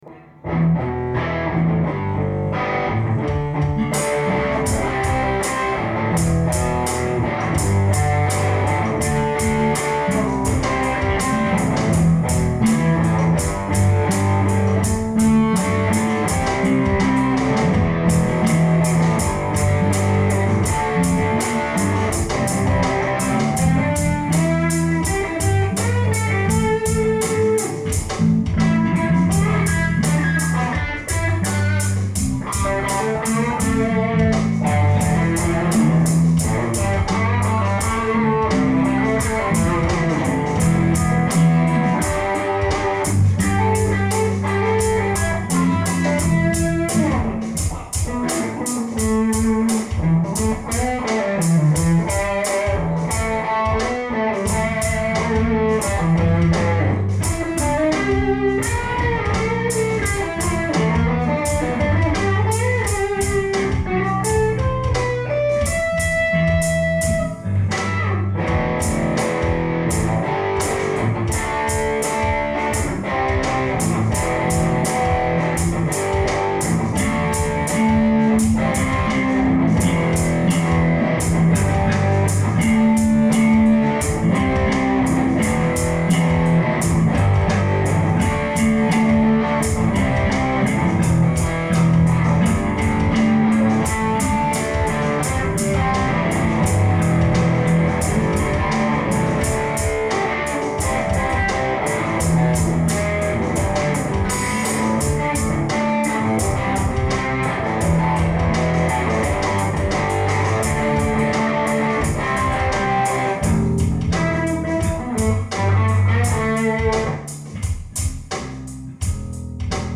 This was a special Jam at Woodside! 2016 ish.